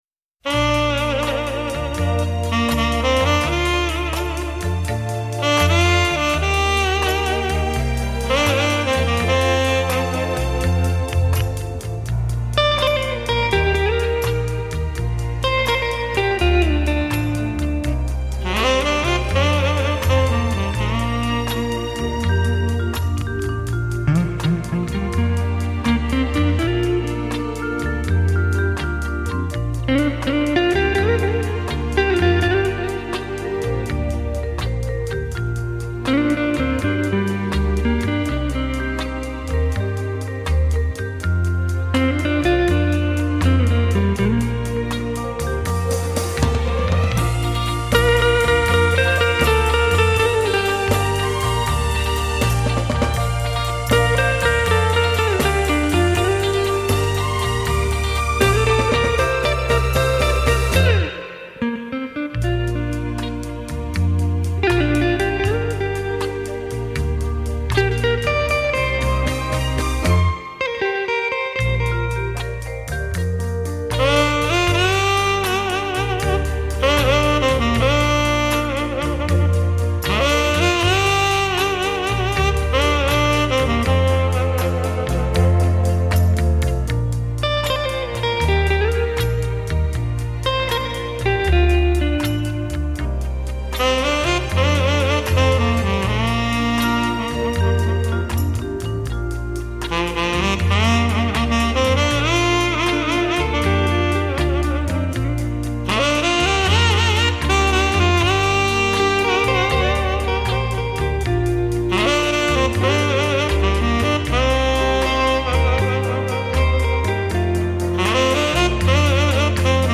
2. 现场模拟录音，1：1的直刻母带技术。